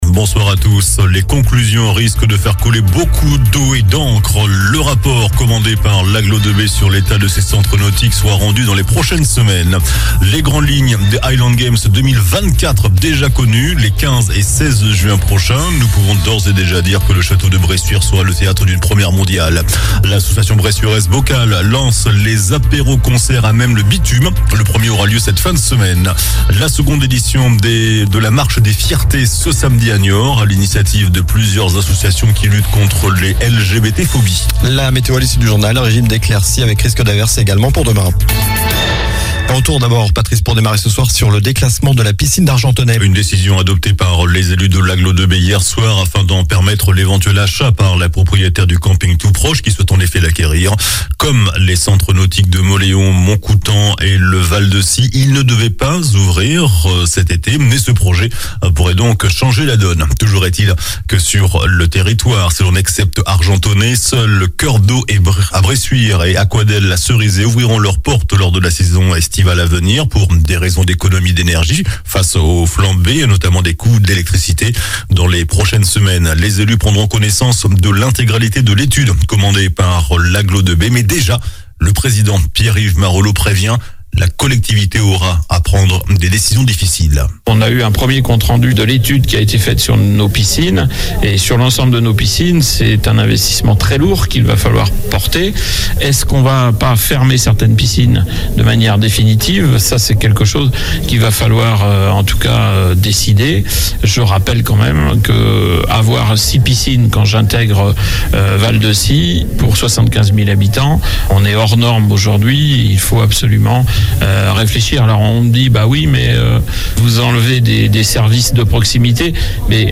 JOURNAL DU MERCREDI 10 MAI ( SOIR )